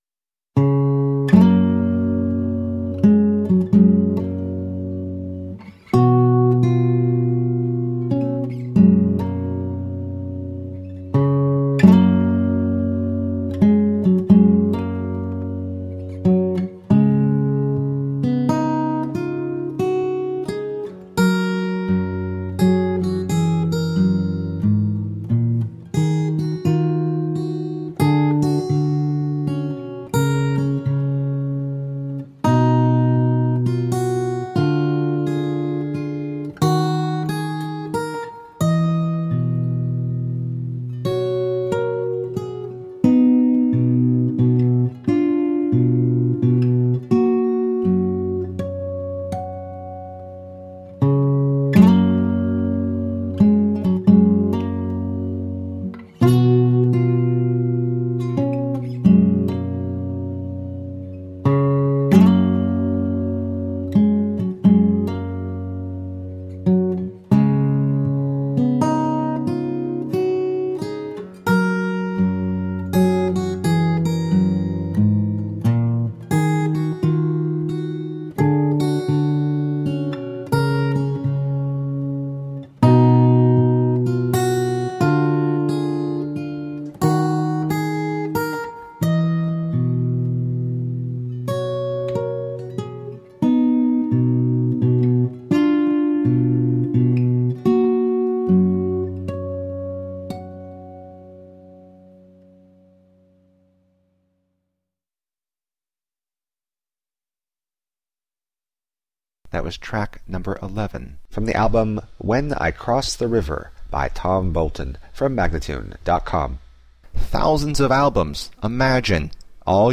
Contemporary folk.
Tagged as: Alt Rock, Folk-Rock, Folk